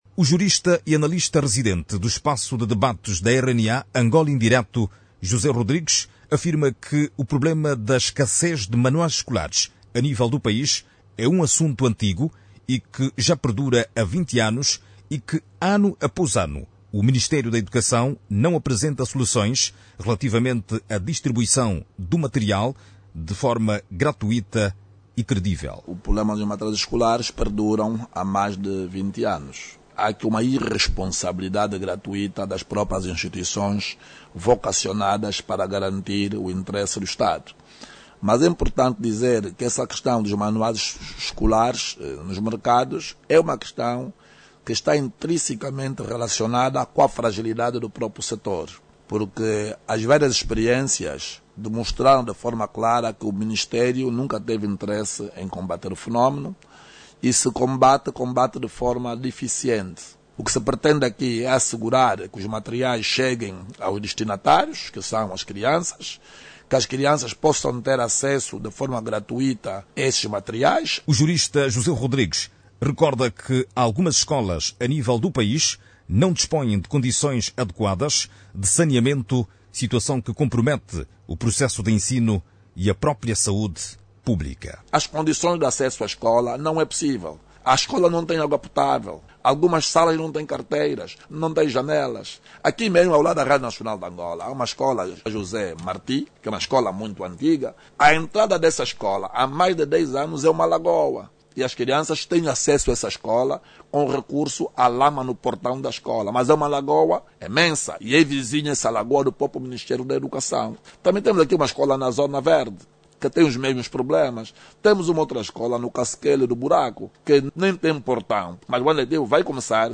Analistas da RNA, defendem mais fiscalização, no processo de distribuição gratuita de matérias escolares, a nível do país. Outra preocupação, prende-se com a escassez de manuais, que durante muitos anos, tem comprometido o processo de ensino e o desempenho dos estudantes. Clique no áudio abaixo e ouça a reportagem